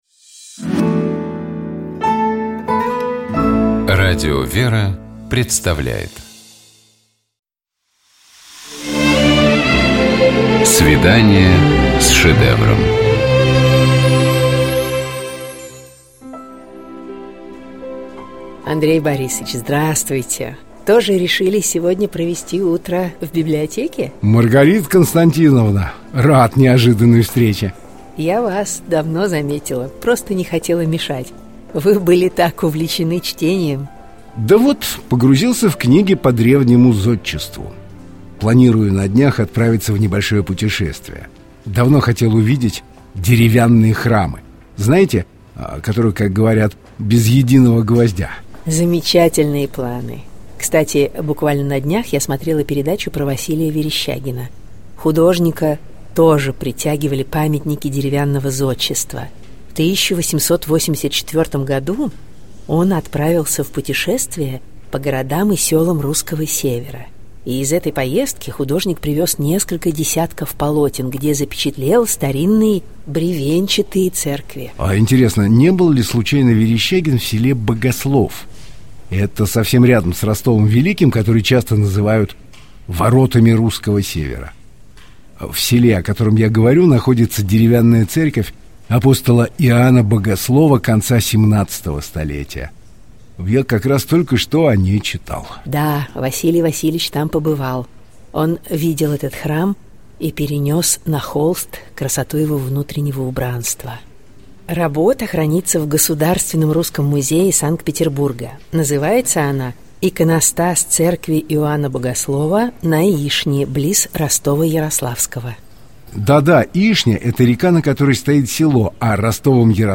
Коридор картиной галереи с посетителями.